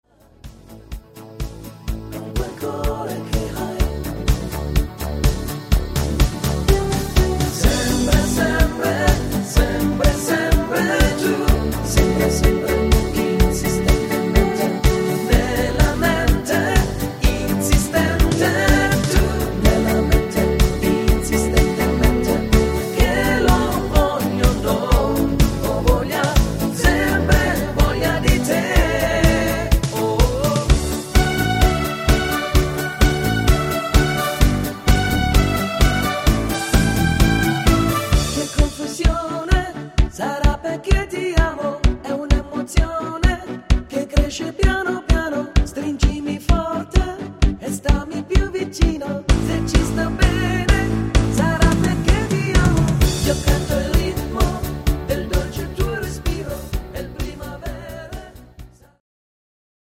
9376  Info   Italia-Hitmix (gut zu singende Tonarten